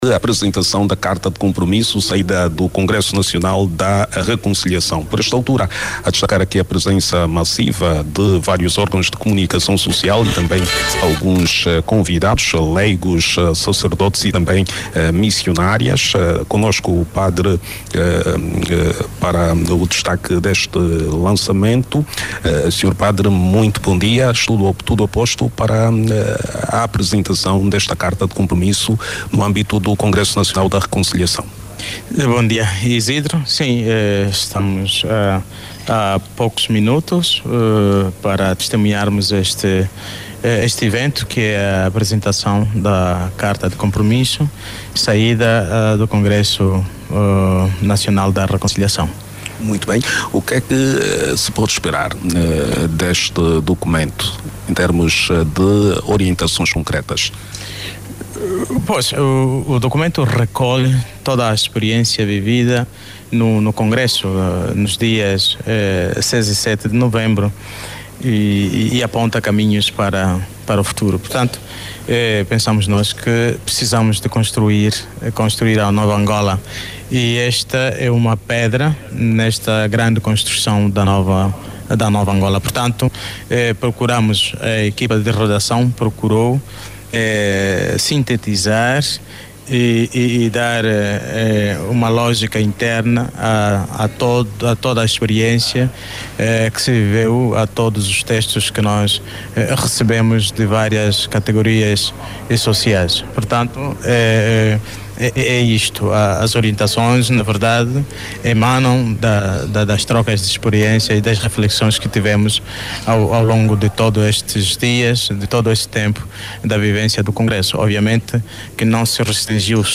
A apresentação da Carta de Compromisso, saída do Congresso Nacional da Reconciliação, decorre num ambiente marcado pela presença massiva de vários órgãos de comunicação social e também de convidados, entre leigos, sacerdotes e missionárias. O padre presente para destacar o lançamento comenta que o momento é de grande expectativa.
Inicia-se então a cerimónia.